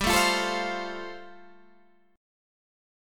G7sus2sus4 chord